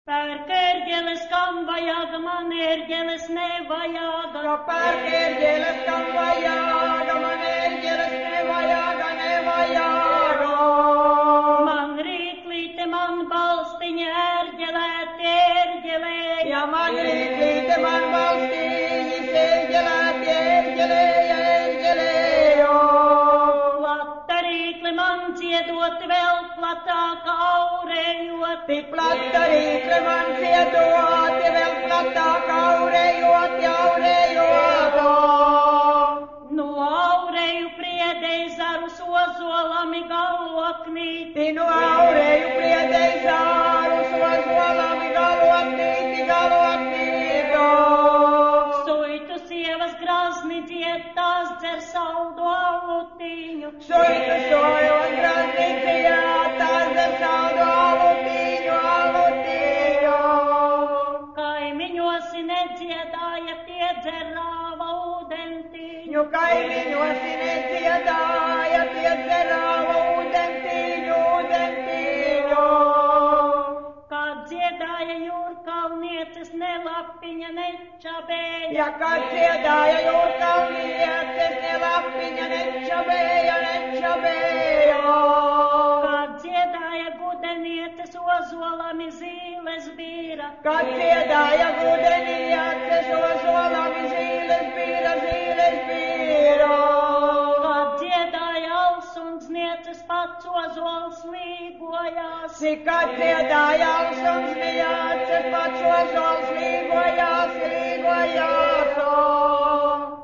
Kā piemēru piedāvāju tev noklausīties Suitu sievu dziedāto
apdziedāšanās dziesmu.
Te tiek pastiepts, te pavilkts, un racionālā viedokļa dziesmas ritmiskums iznāk diezgan apšaubāms, kamēr emocionālais ir neapstrīdams.